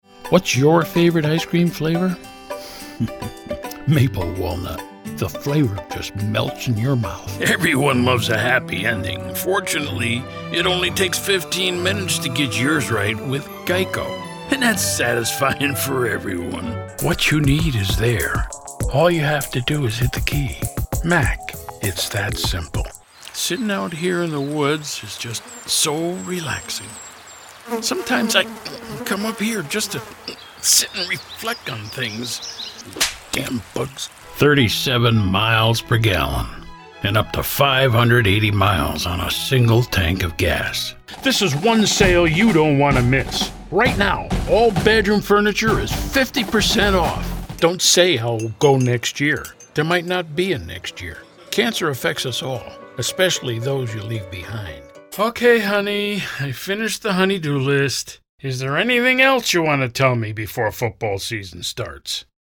mid-atlantic
Sprechprobe: Werbung (Muttersprache):
WARM, GENUINE,INFORMATIVE, GUY NEXT DOOR